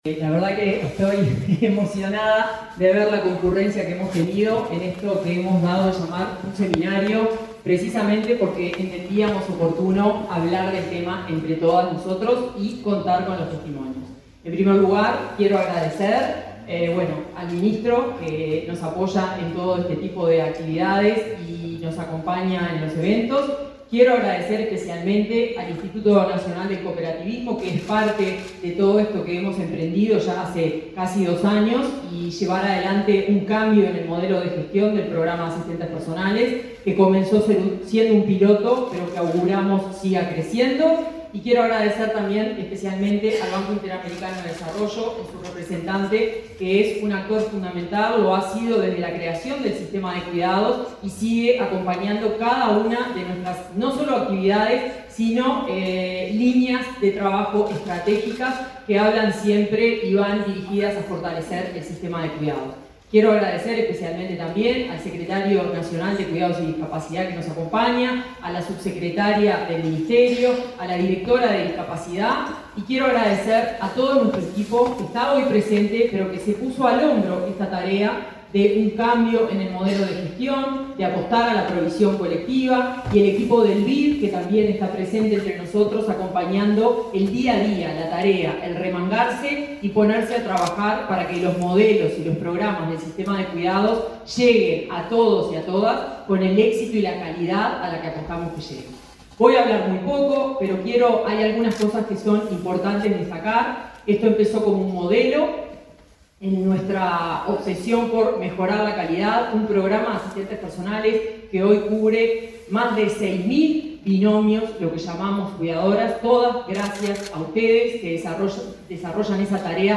Palabra de autoridades en acto del Mides
Palabra de autoridades en acto del Mides 18/04/2024 Compartir Facebook X Copiar enlace WhatsApp LinkedIn La directora de Cuidados del Ministerio de Desarrollo Social (Mides), Florencia krall; el presidente del Instituto Nacional del Cooperativismo, Martín Fernández, y el ministro Alejandro Sciarra participaron, este jueves 18 en Montevideo, en una jornada de difusión de experiencias de las asistentes personales que trabajan bajo la modalidad de cooperativa.